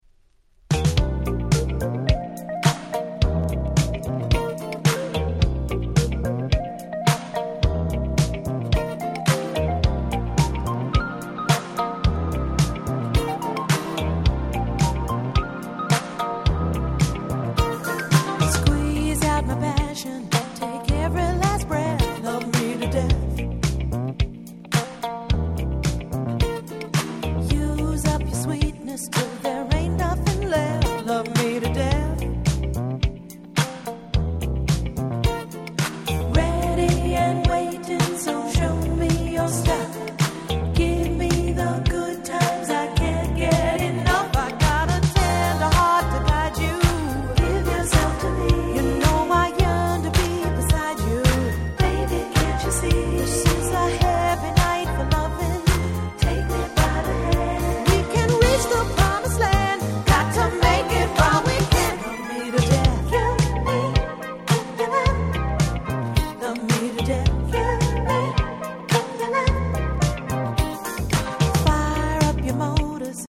81' Super Hit Disco/Dance Classic !!